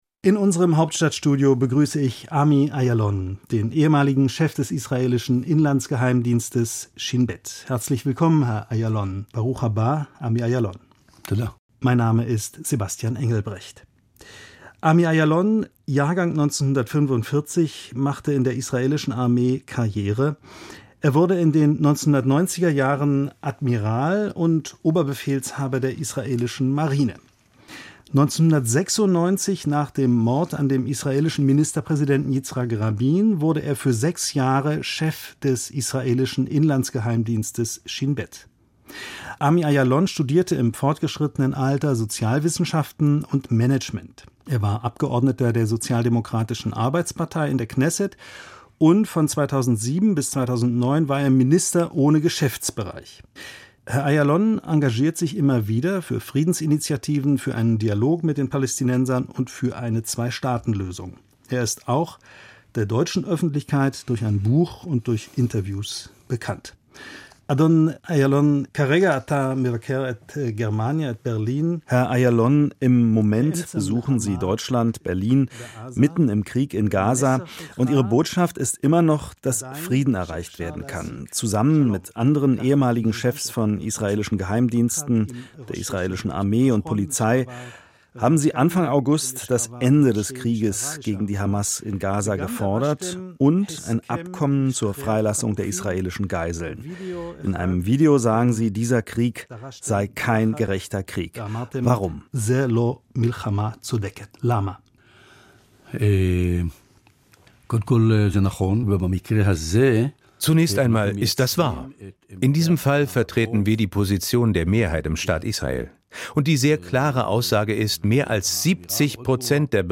Interview der Woche: Ami Ajalon